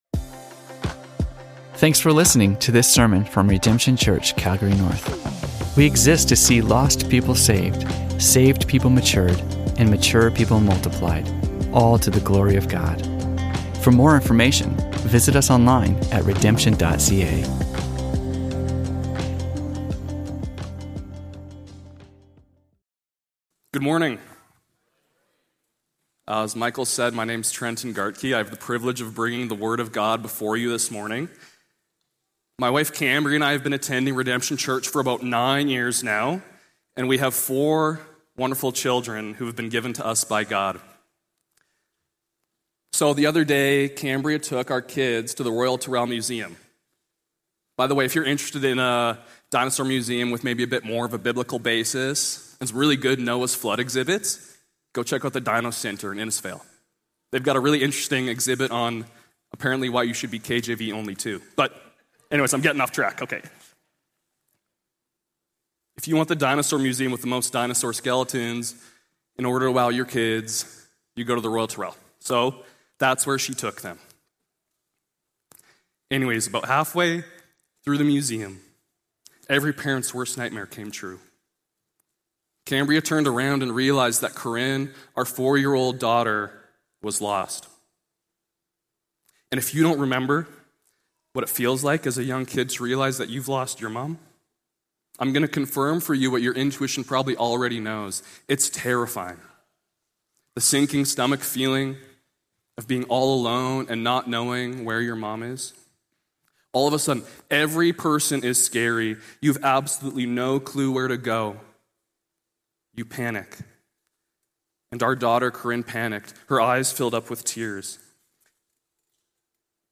Sermons from Redemption Church Calgary North